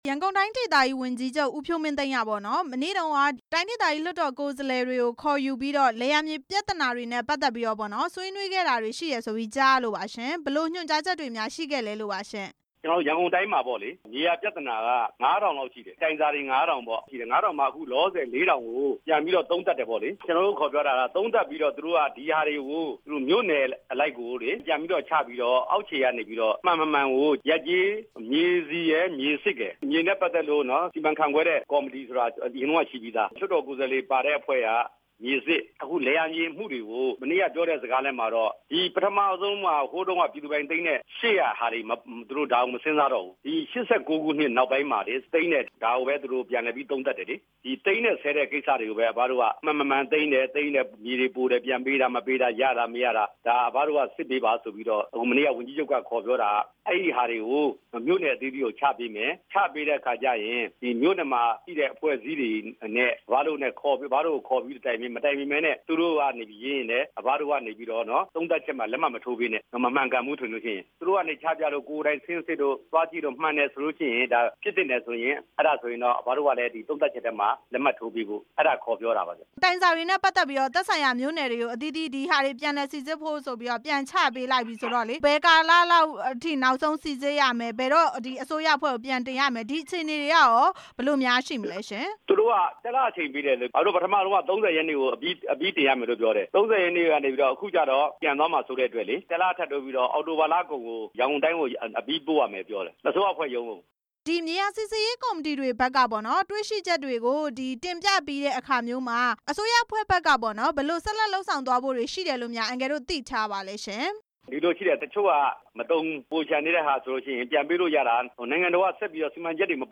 ဆက်သွယ်မေးမြန်းထားပါတယ်။